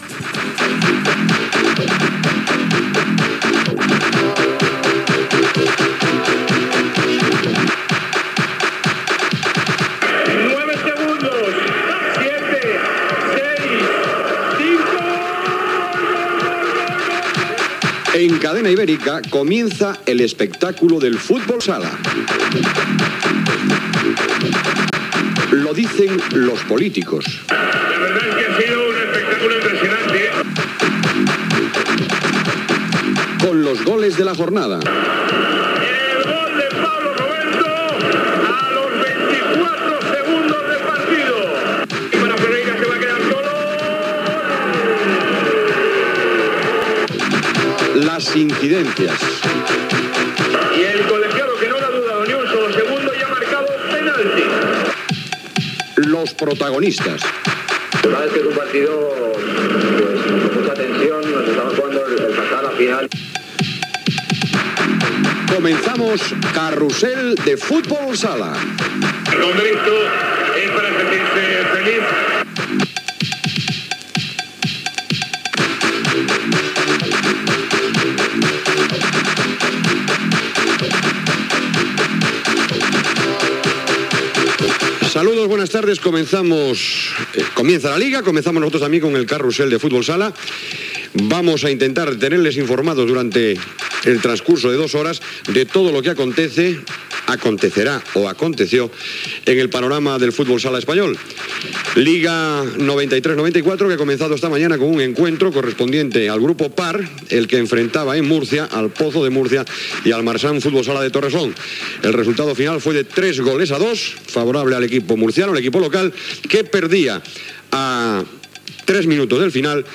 Careta del programa, presentació, informació del partit El Pozo de Murica - Marsans Torrejón
Esportiu
FM